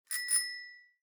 Index of /phonetones/unzipped/Lenovo/A6000/notifications
Single_Clang.ogg